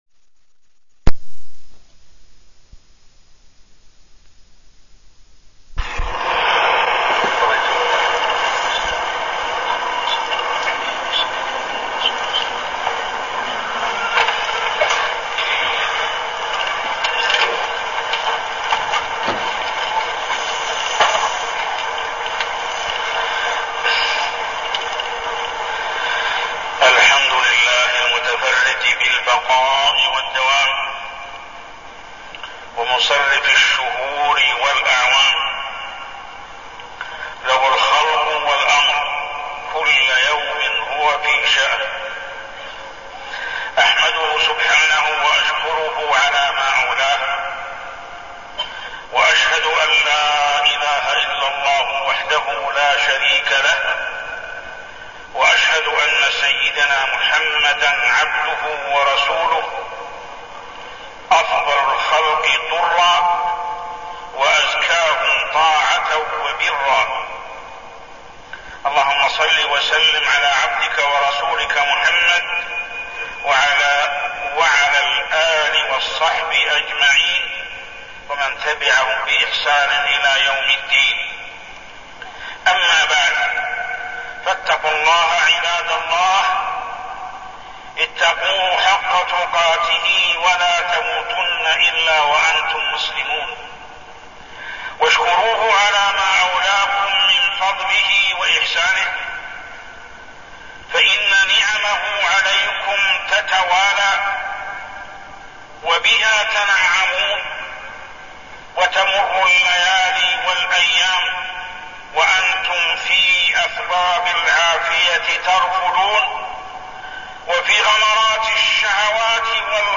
تاريخ النشر ٤ محرم ١٤١٦ هـ المكان: المسجد الحرام الشيخ: محمد بن عبد الله السبيل محمد بن عبد الله السبيل صبر النبي صلى الله عليه وسلم